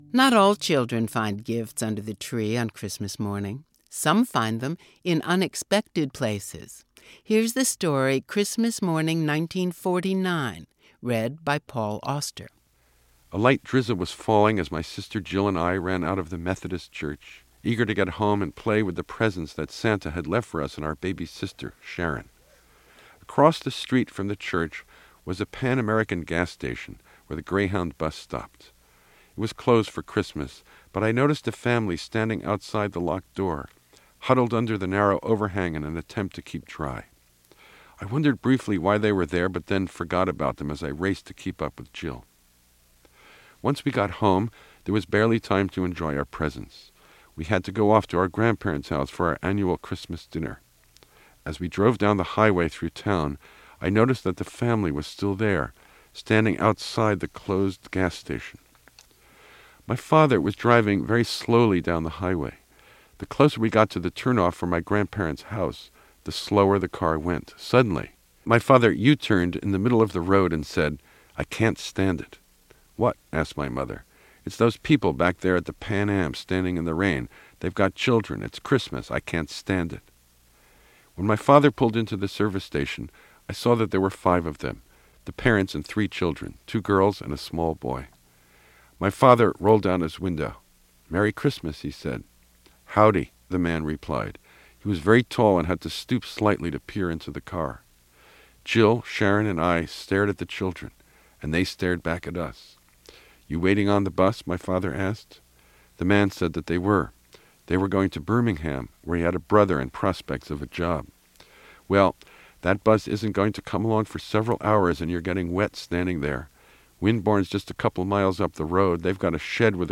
Novelist Paul Auster reads a story written by Sylvia Seymour Akin about a special Christmas.